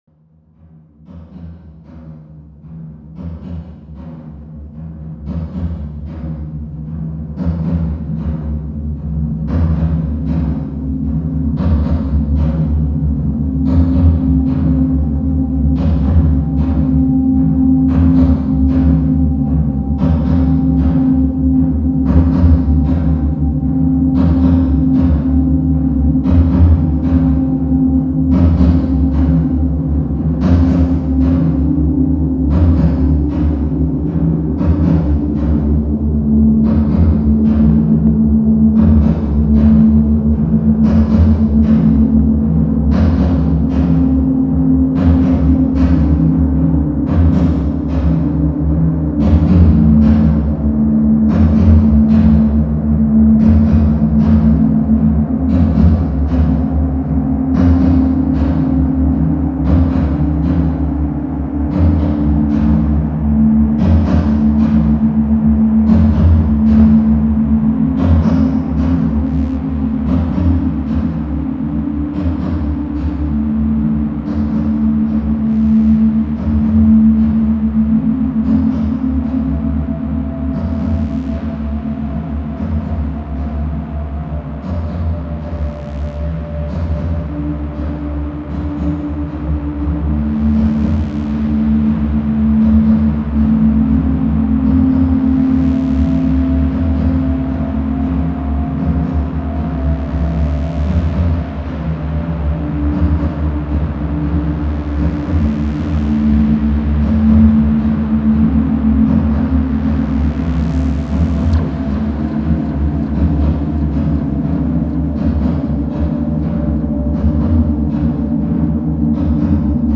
*фоновая композиция –